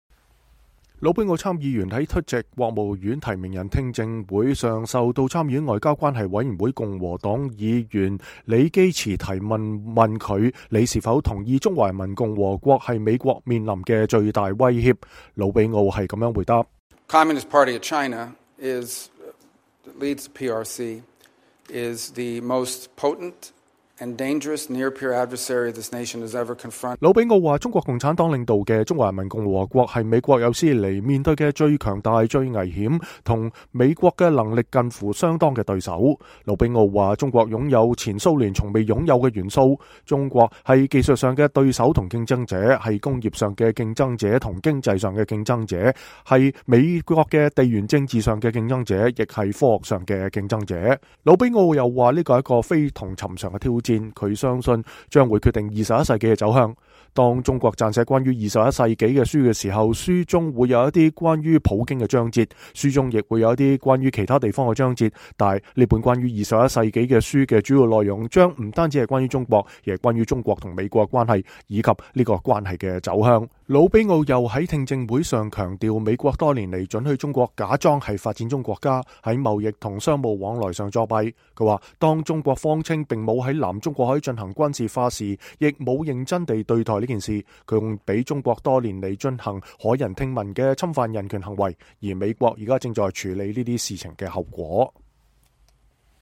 候任總統特朗普的國務卿提名人魯比奧1月15日在確認聽證會上發言。（美聯社照片）